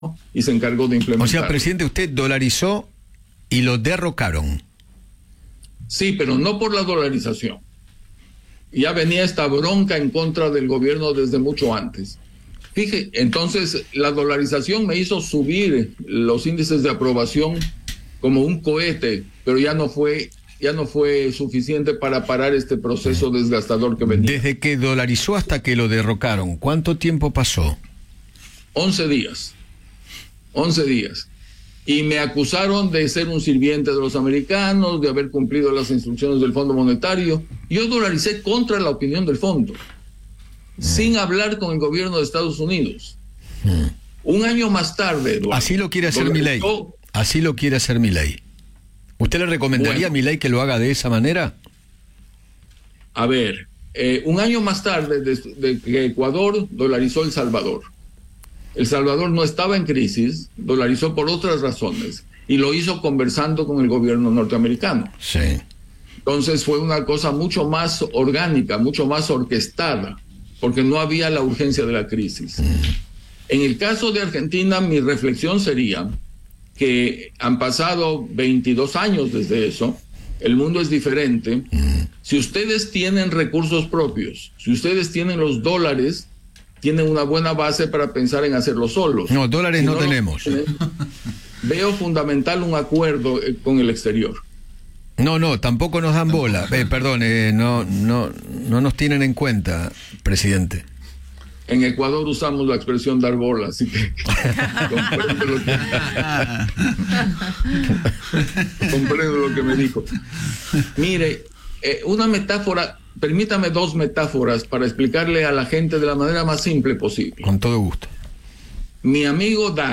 Jamil Mahuad, ex presidente de Ecuador, conversó con Eduardo Feinmann sobre el proceso de dolarización en aquel país y analizó sus efectos.